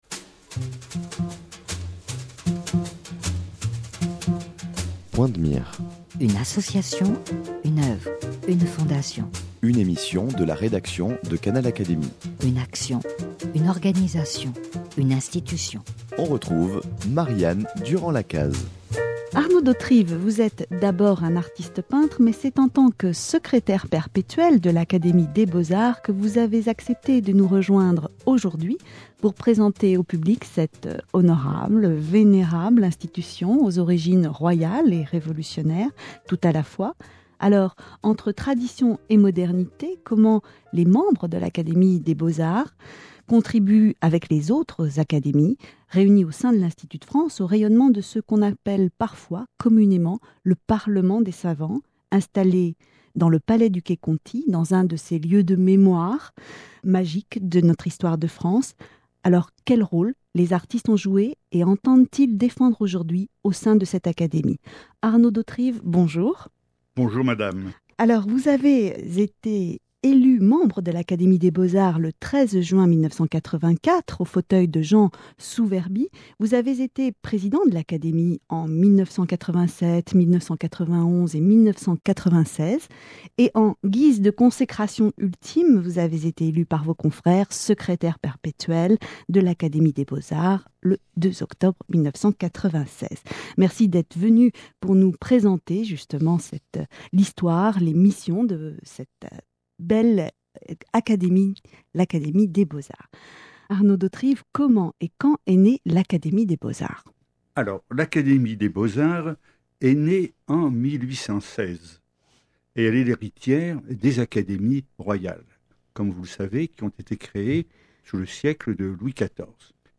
Quel rôle les artistes ont joué et entendent-ils défendre aujourd’hui au sein de l’Académie des beaux-arts ? Le Secrétaire perpétuel de l’Académie depuis 1996, Arnaud d’Hauterives présente l’histoire, l’organisation et les missions de la Compagnie qu’il incarne par sa fonction.